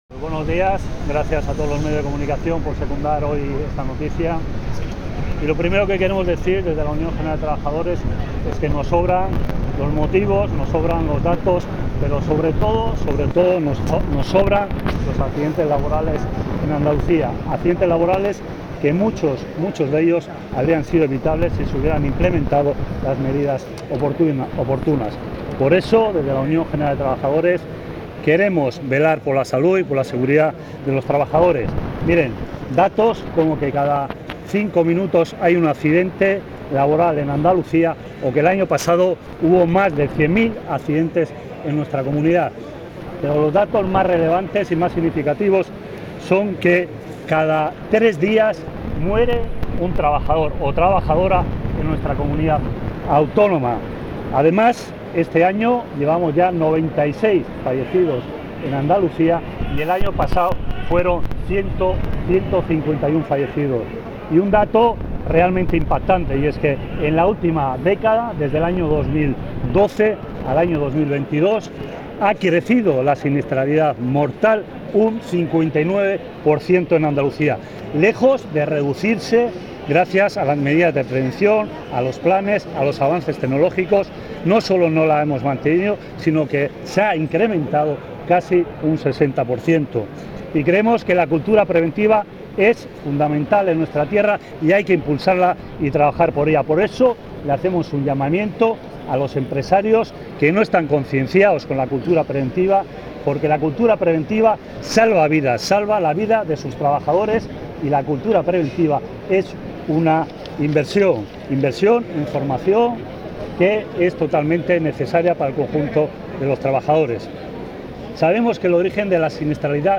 han atendido a los medios de comunicación al inicio de la manifestación, que ha partido de Plaza Nueva y ha terminado con alrededor de 2000 personas en la Setas de la Encarnación de Sevilla.